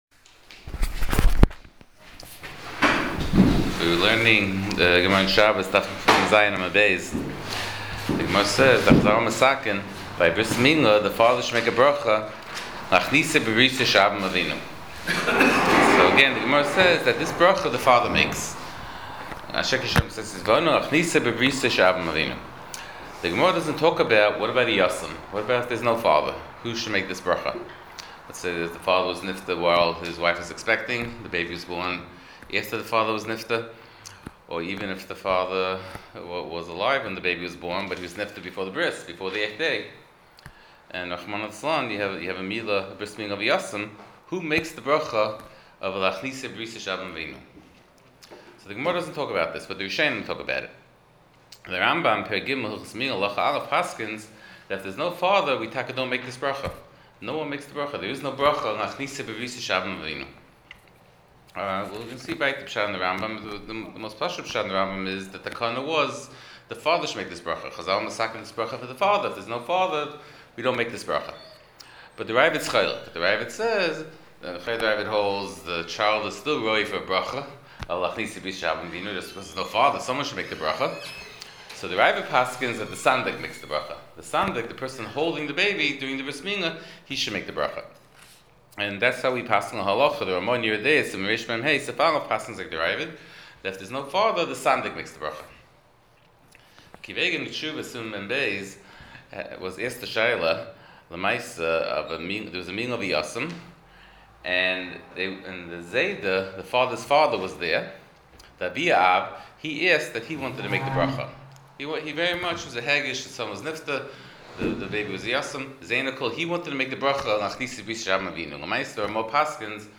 Tshuvos RA''E Siman 42 Shiur provided courtesy of Madison Art Shop.